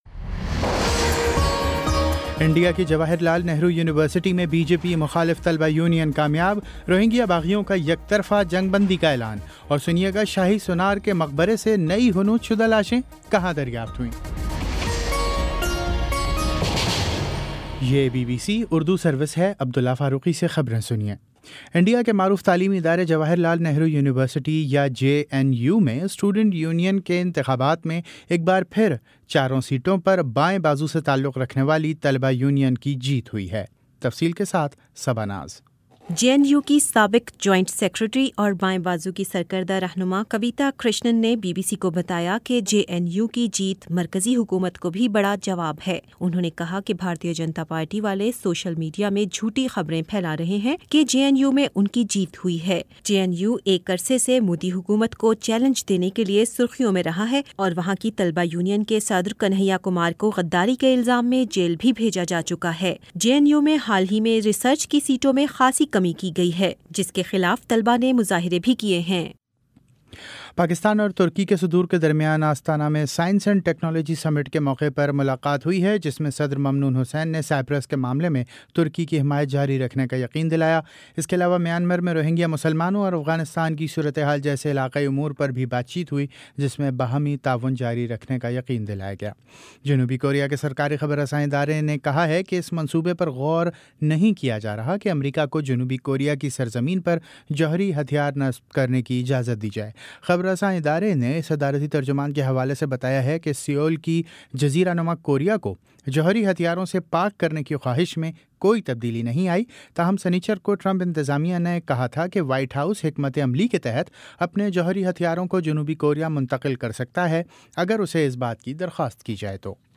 ستمبر 10 : شام چھ بجے کا نیوز بُلیٹن